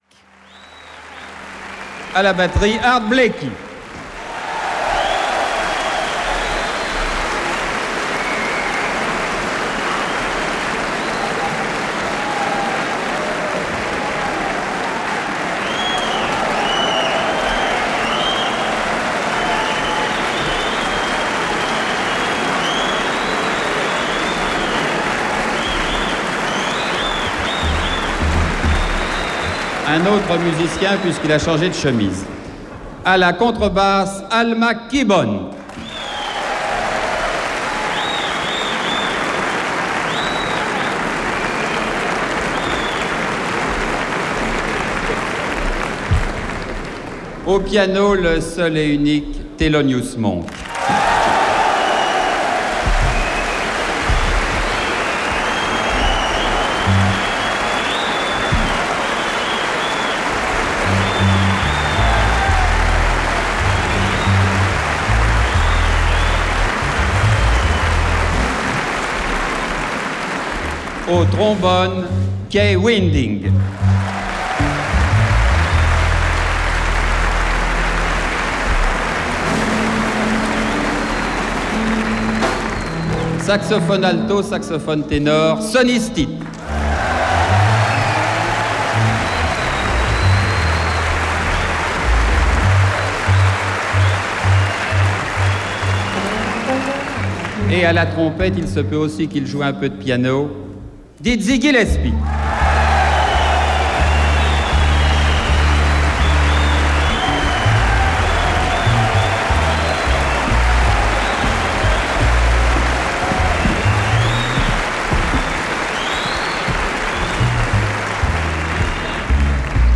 Recorded at the Palais de Chaillot, Paris